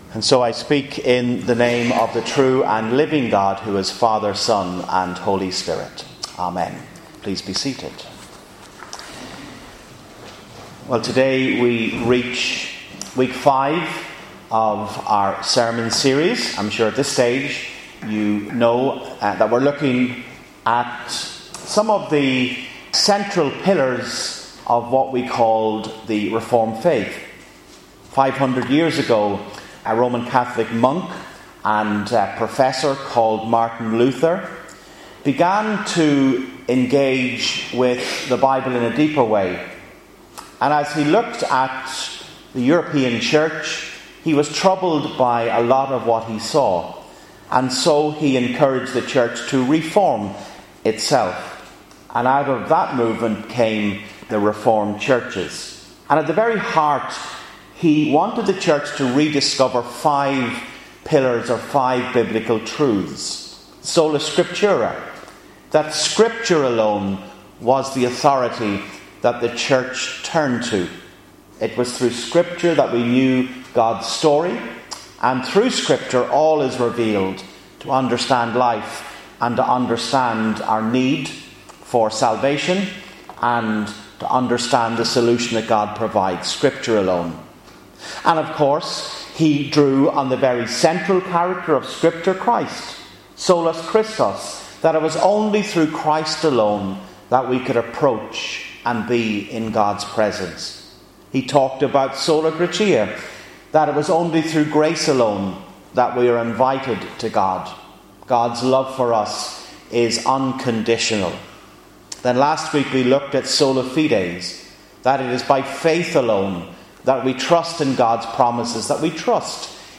Sermon Series: 5 Solas - Virginia Group Church Of Ireland